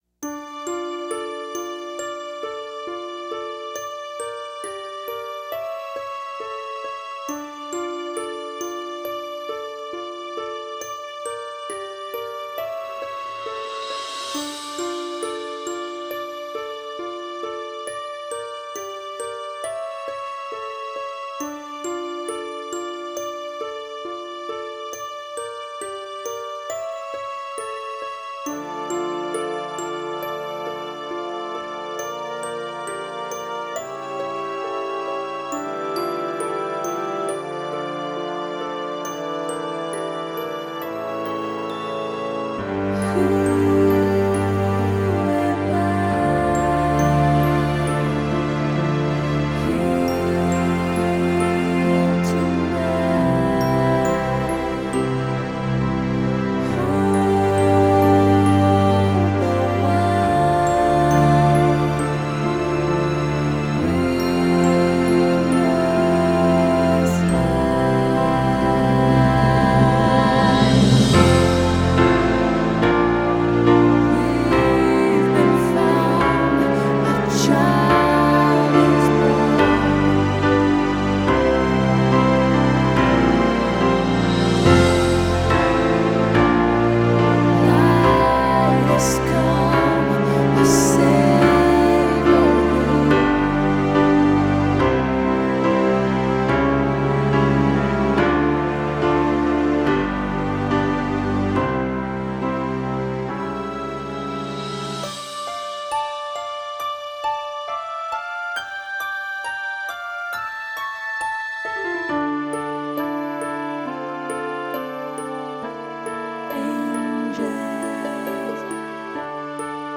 02 Hallelujah (Light Has Come) - Backtrack.m4a